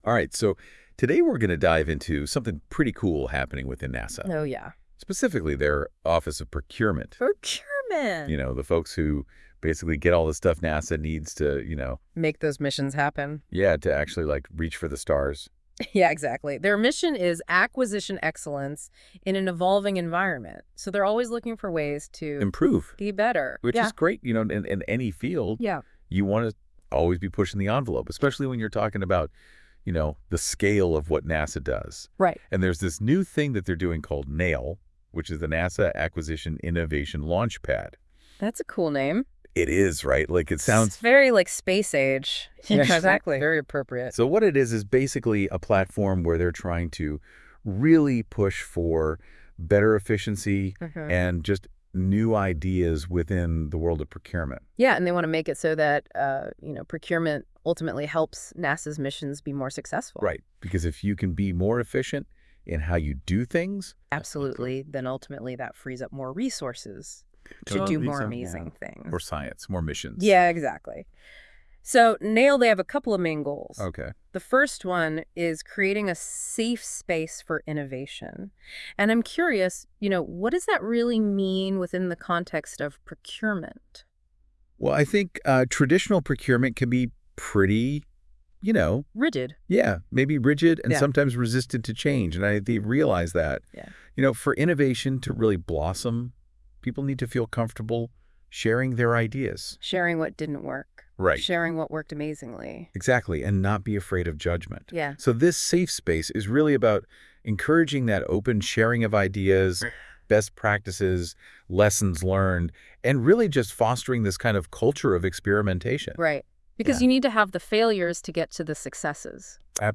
Powered by AI for Cost-Effective Training: With the recent introduction of Google NotebookLM, we’re leveraging AI to create AI-driven podcasts from essential procurement documents.